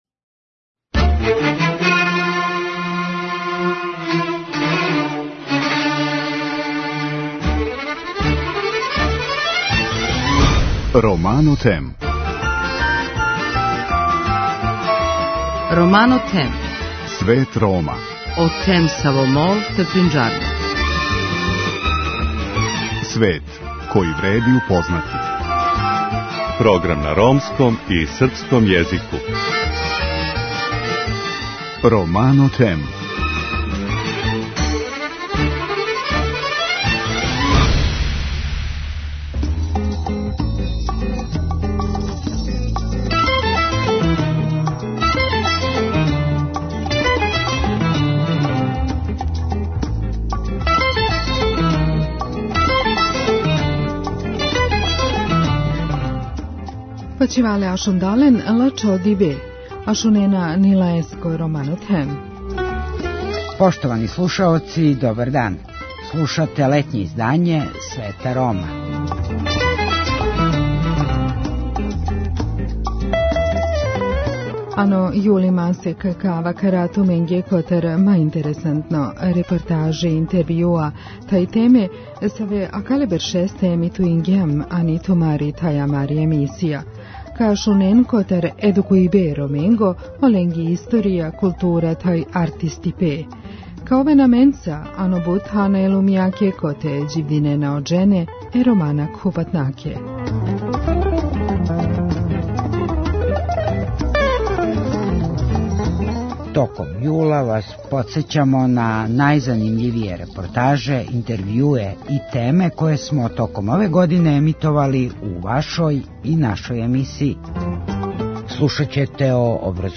Ромске песме